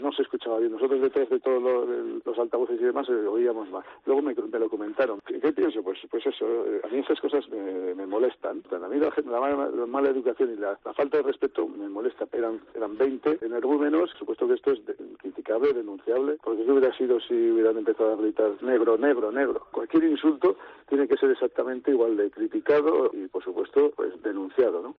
El seleccionador español ha criticado en Radio Marca los cánticos de "calvo, calvo" que recibió el lunes en la celebración de la Nations League: "las faltas de respeto me molestan"